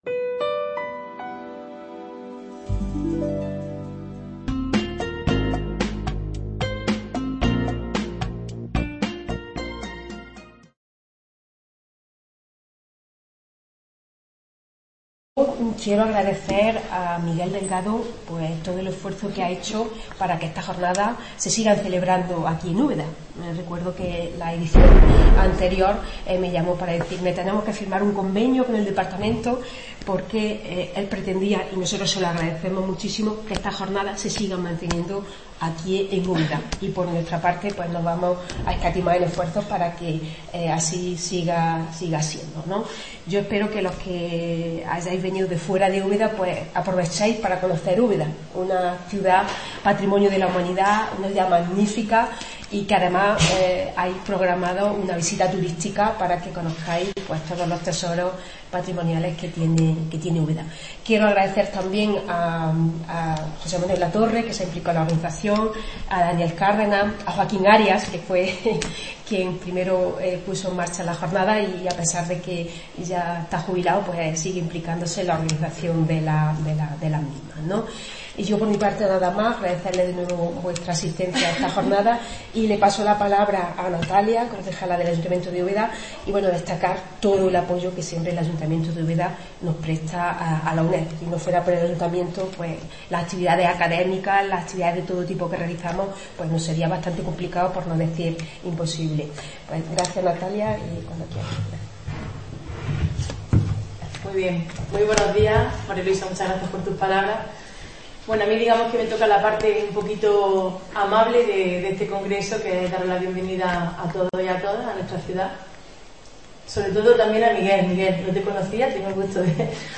Inauguración oficial EXIDO22
Aula Virtual del Centro Asociado de Jaén